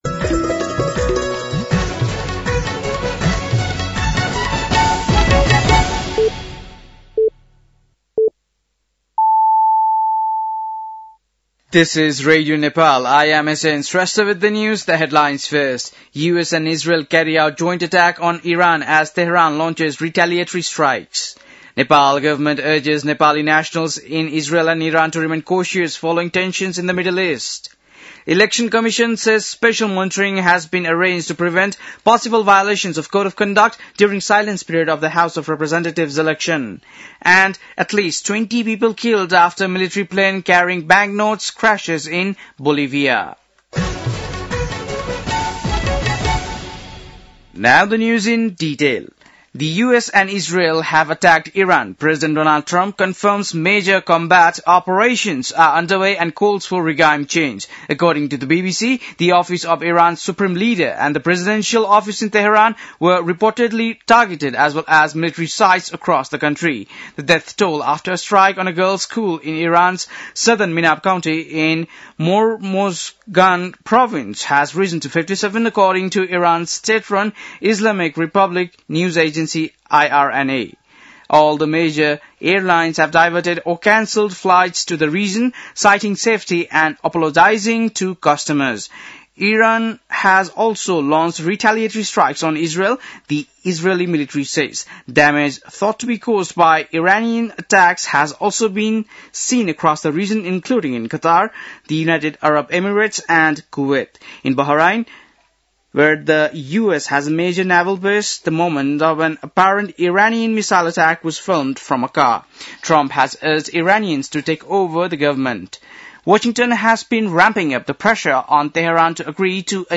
बेलुकी ८ बजेको अङ्ग्रेजी समाचार : १६ फागुन , २०८२